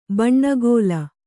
♪ baṇṇagōla